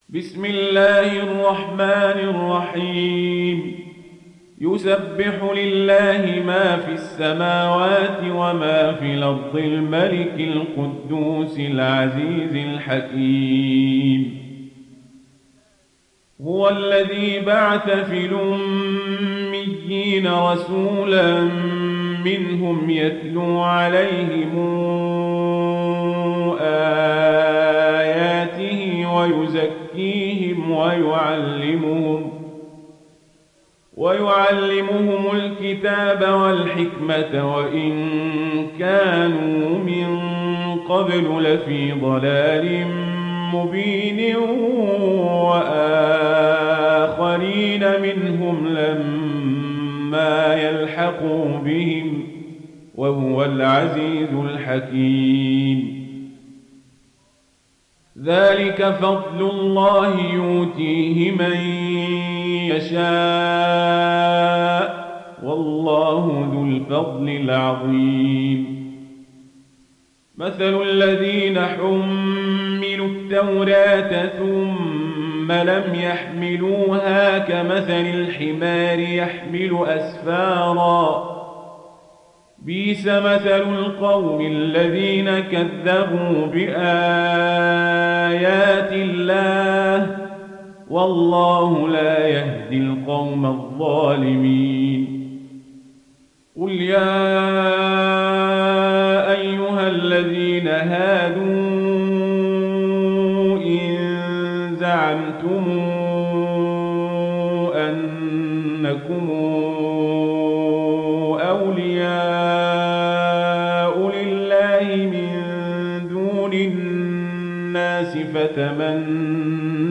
Warsh থেকে Nafi